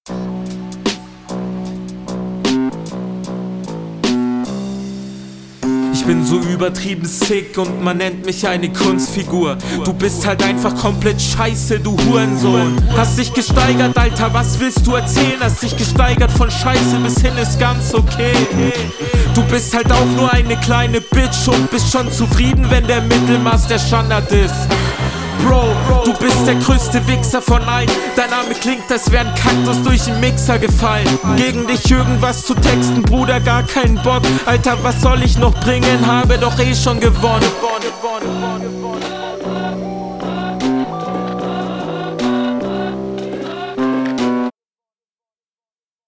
Solider Front von dir, Qualität ist gut das einzige was jetzt noch fehlt sind wirklich …